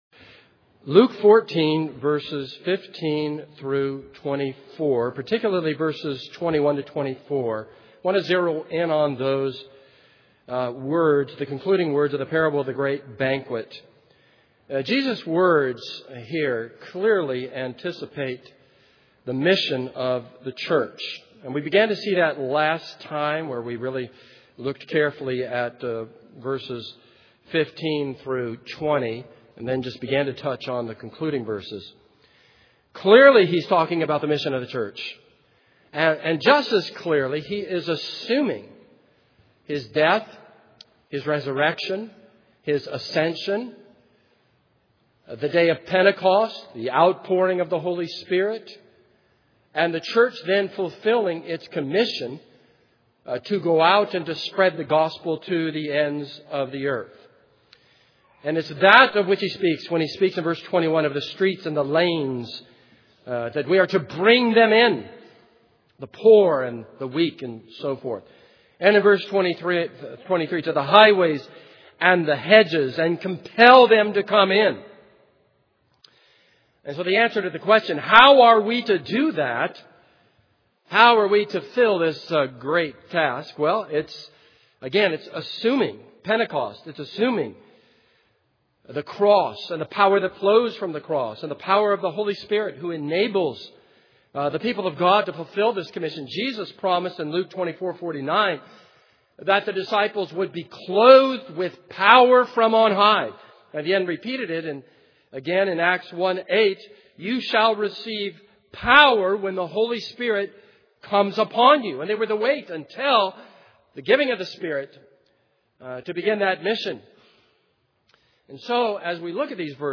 This is a sermon on Luke 14:21-24